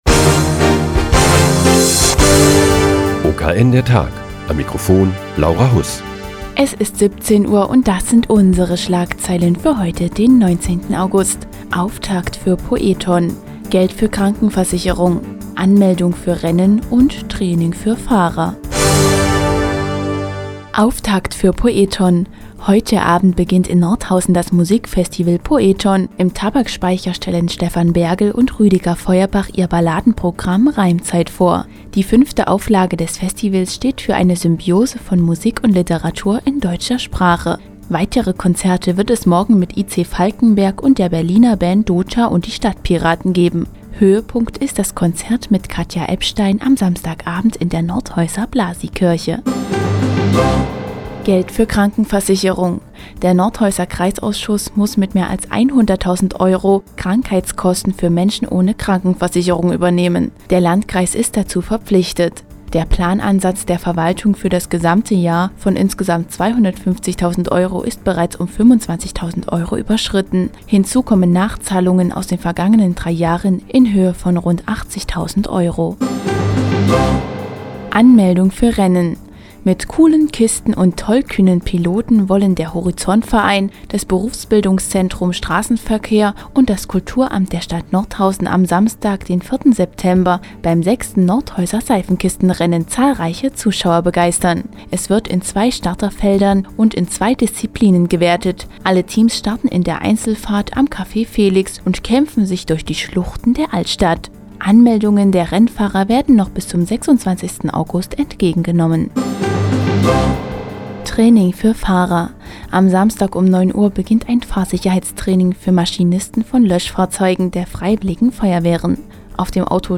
Die tägliche Nachrichtensendung des OKN ist nun auch in der nnz zu hören. Heute geht es um das 6. Nordhäuser Seifenkistenrennen und ein Fahrsicherheitstraining für Maschinisten von Löschfahrzeugen der Freiwilligen Feuerwehren.